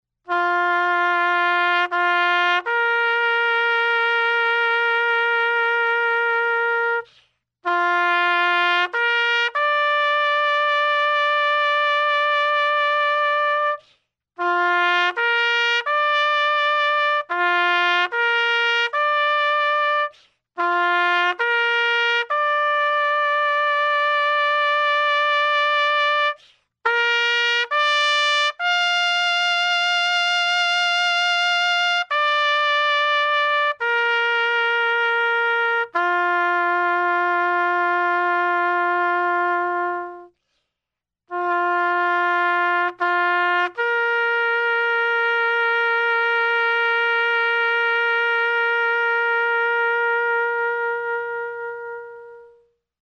When we hear the military bugle call, we know today as Taps, we do more than hear it: we feel it. It has varied meaning for those who embrace the smooth intervals which start in the middle register and rise the cusp of a bugle’s high register only to waft gently downward again.
The session was short and sweet, much to the surprise of the engineer who anticipated several takes and possible editing. What he got was one take and done.